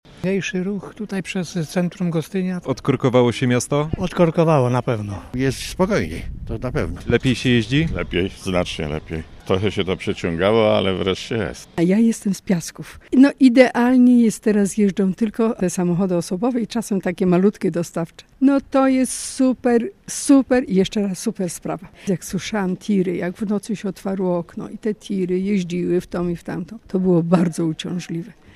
"Poprawa jest wyraźna" - mówią mieszkańcy w rozmowie z reporterem Radia Poznań.